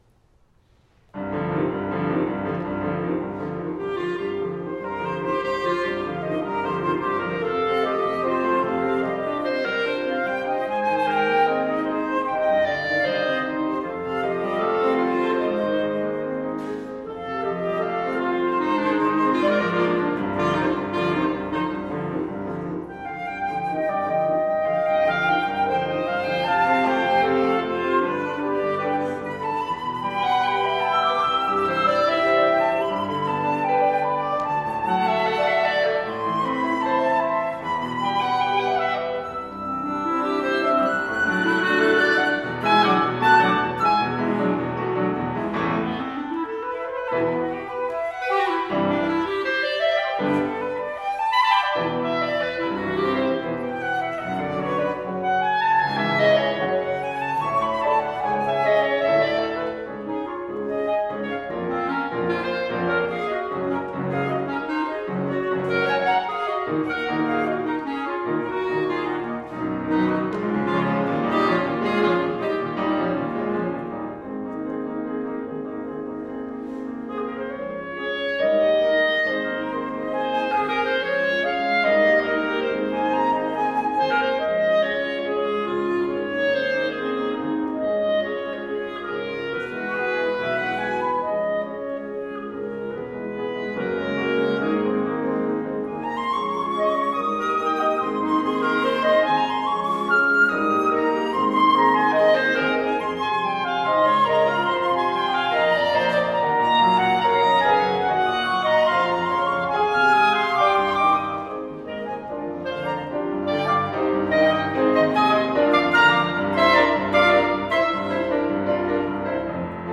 Flute, Faculty Artist Recital
Thursday, October 4, 2018 7:30 p.m. All Faiths Chapel Auditorium
Music, Theatre, and Dance Faculty Recitals